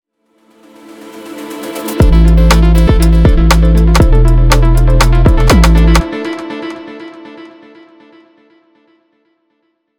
Podcast Überleitung zum nächsten Abschnitt 2
Podcast-Transition-2.wav